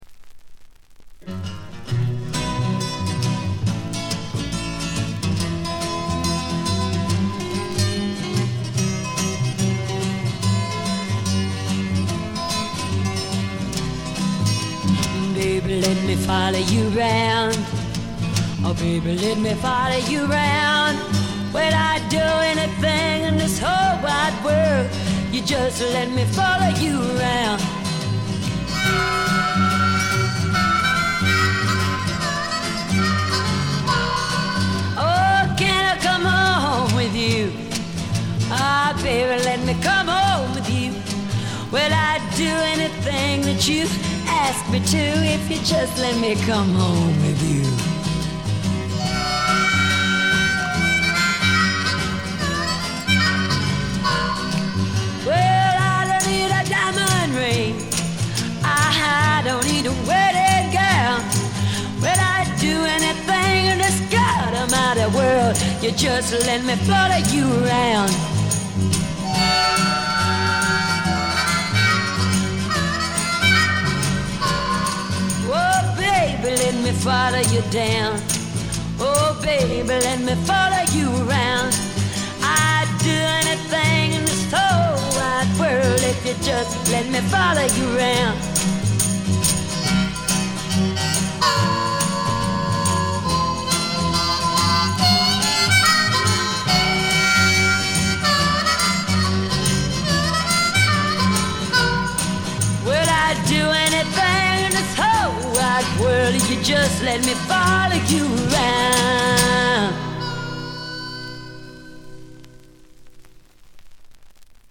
全体に細かなチリプチ、バックグラウンドノイズが出ていますが気になるのはこのB1ぐらい。
美しいフォーク・アルバムです。
最初期のモノラル盤。
試聴曲は現品からの取り込み音源です。